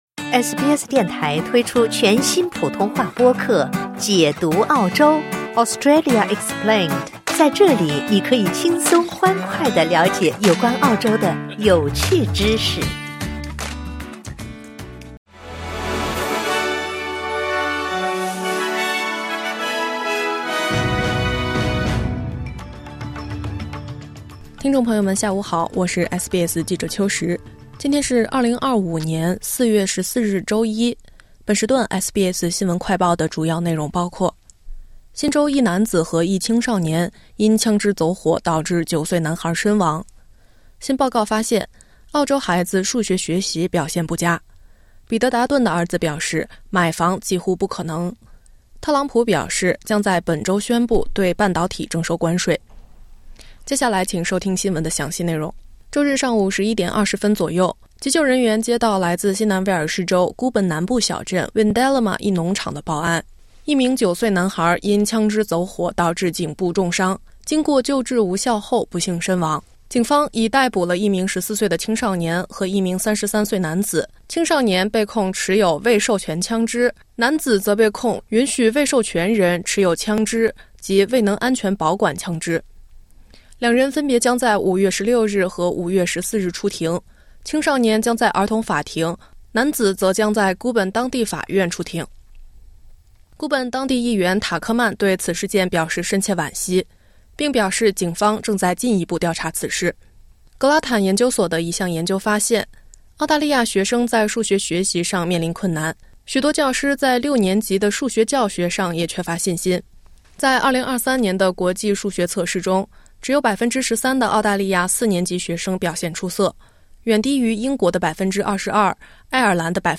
【SBS新闻快报】枪支走火致9岁新州男孩身亡 一男子一少年被控